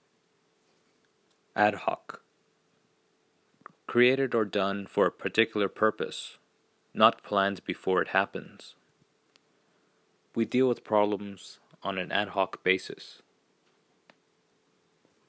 一般の会話というよりも、IT関連や流通業、法律関係などビジネス英語としてよく用いられる表現です 英語ネイティブによる発音は下記のリンクをクリックしてください。